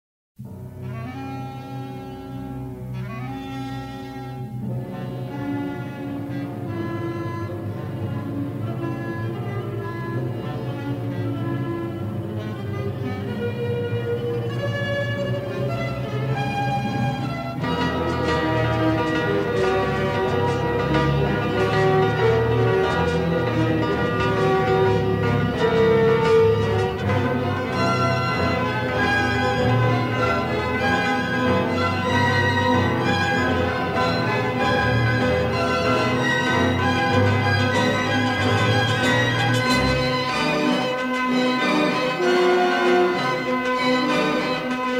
in their definitive stereo editions
Hungarian cymbalom instrument as a flamboyant color
original 1/4" stereo tape